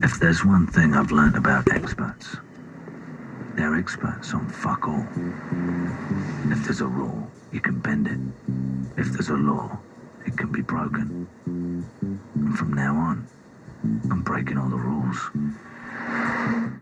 The perfect film audio clip for these times!!! (funny)
It sounds like Jason Statham to me!